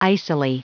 Prononciation du mot icily en anglais (fichier audio)
Prononciation du mot : icily